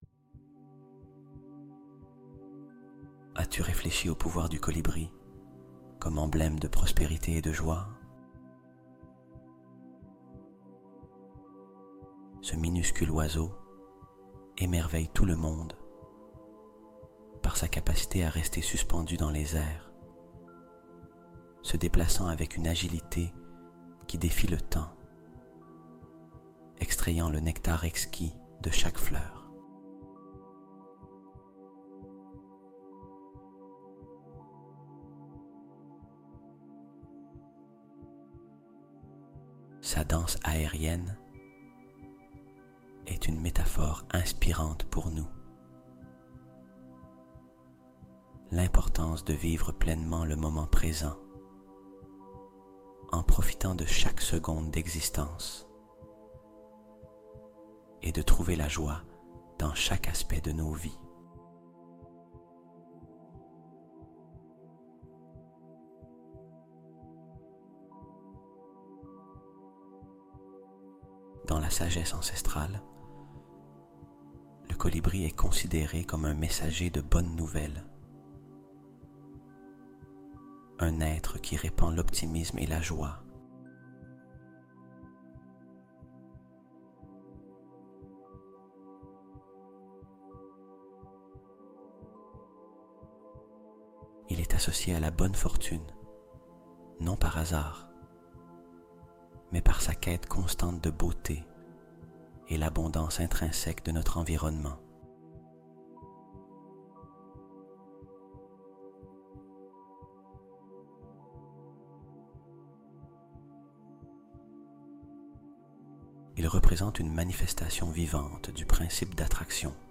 LA HARPE MAGIQUE QUI ATTIRE L'ARGENT ET L'AMOUR COMME UN AIMANT | Écoute Et Regarde Les Miracles Arriver